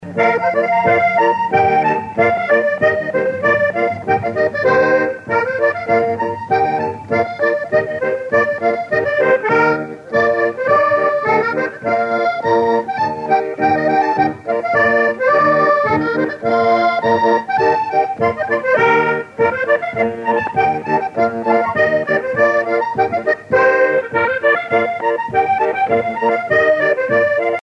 danse : scottich trois pas
Sonneur d'accordéon